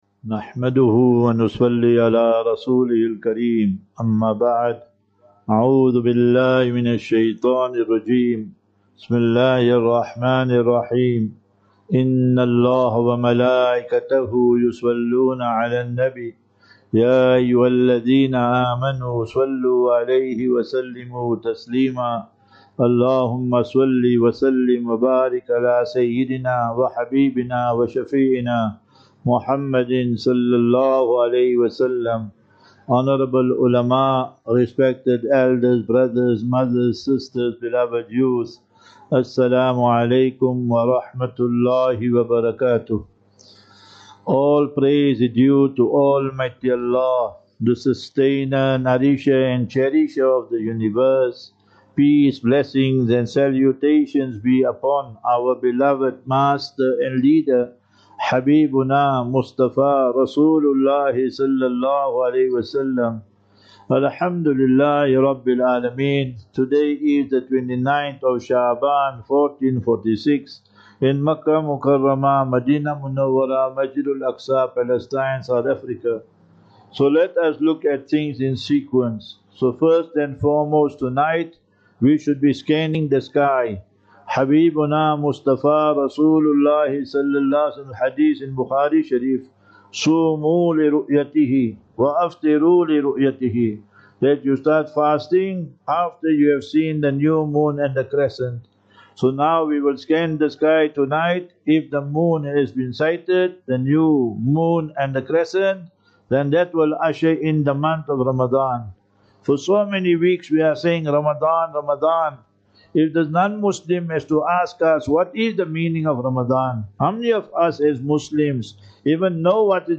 Jumu'ah Lecture